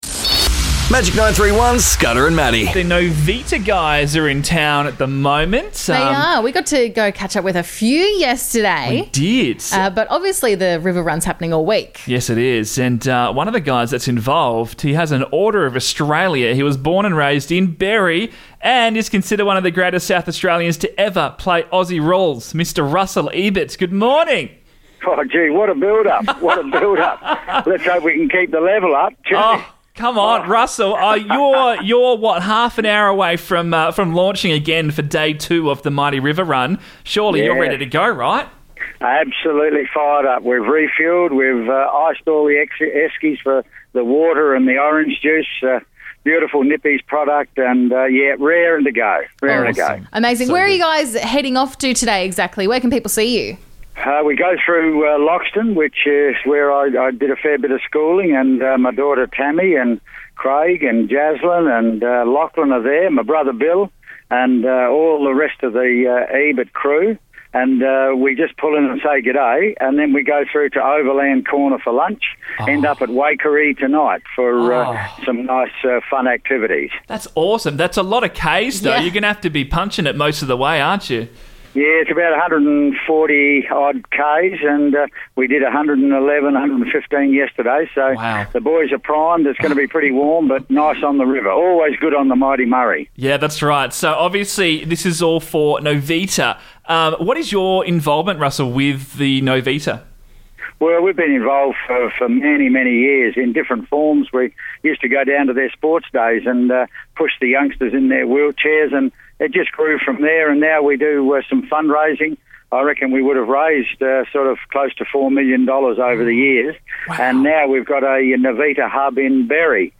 SA Aussie Rules legend Russell Ebert joined us on air this morning to chat about the Mighty River Run - Heading to Loxton and Waikerie today!